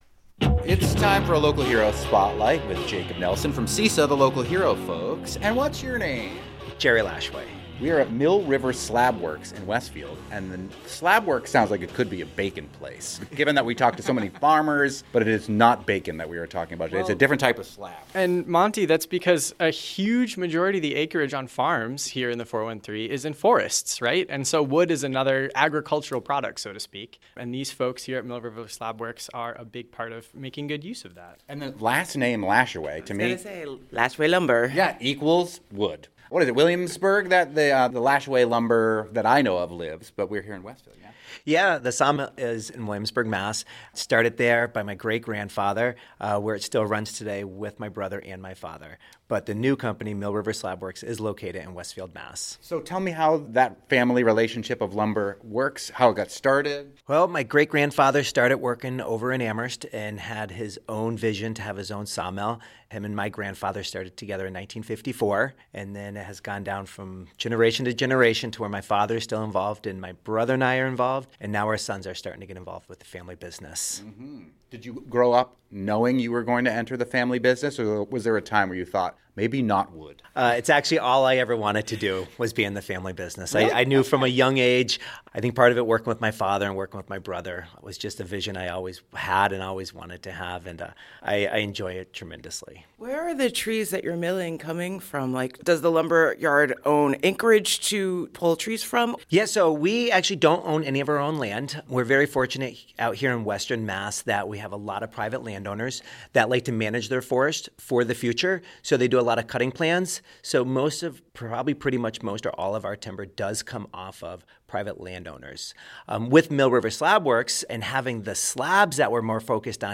NEPM "Fabulous 413" interviews